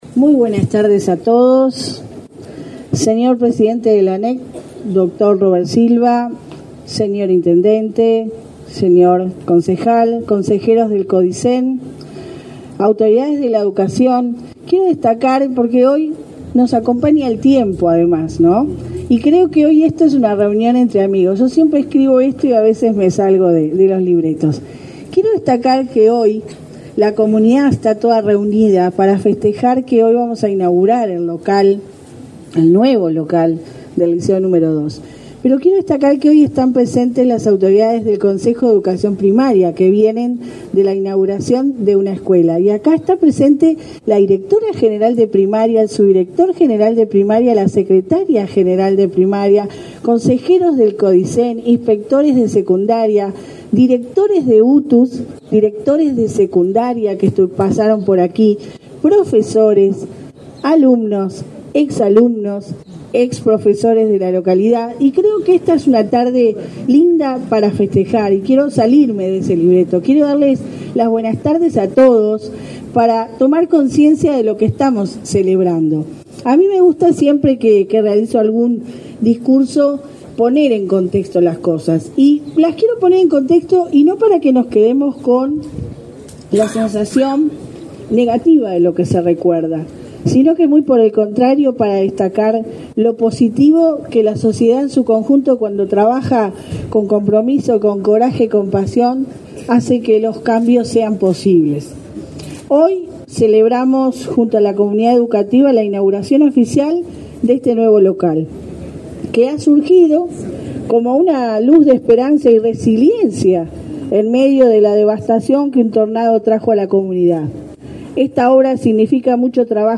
Palabras de la directora general de Secundaria, Jenifer Cherro
Palabras de la directora general de Secundaria, Jenifer Cherro 19/10/2023 Compartir Facebook X Copiar enlace WhatsApp LinkedIn La Administración Nacional de Educación Pública (ANEP) inauguró, este 19 de octubre, el edificio del liceo n.º 2 de Dolores. En el evento, la directora general de Educación Secundaria, Jenifer Cherro, realizó declaraciones.